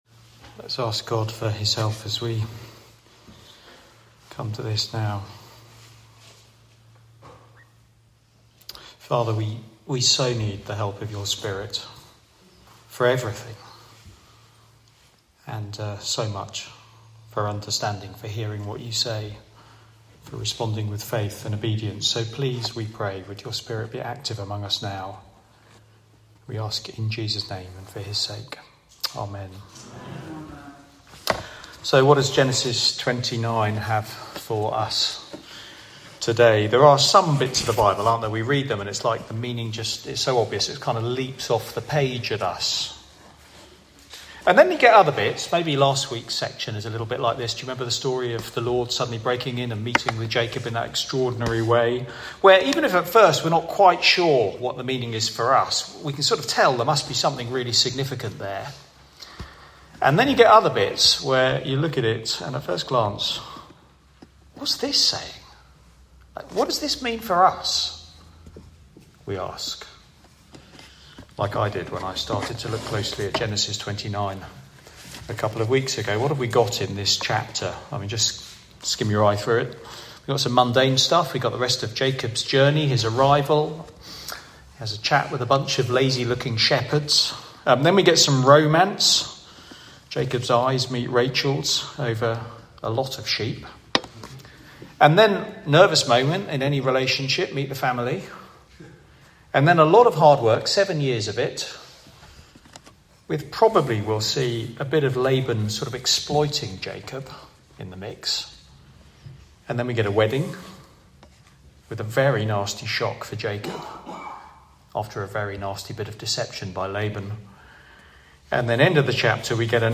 Media for Sunday Evening on Sun 08th Jun 2025 18:00
Sermon